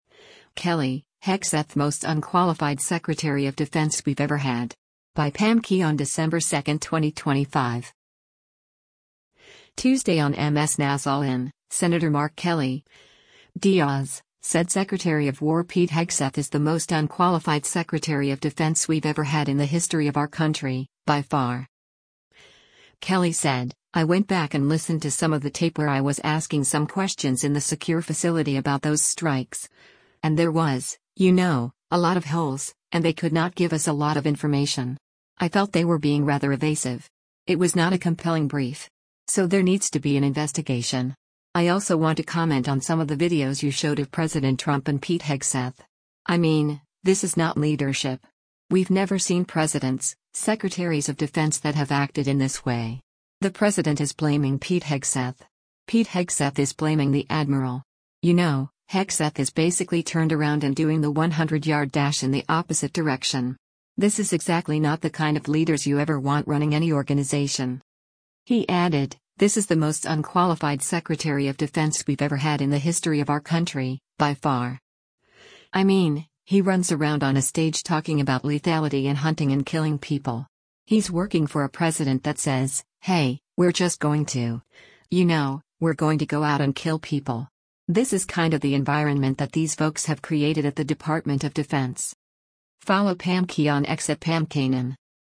Tuesday on MS NOW’s “All In,” Sen. Mark Kelly (D-AZ) said Secretary of War Pete Hegseth “is the most unqualified secretary of defense we’ve ever had in the history of our country, by far.”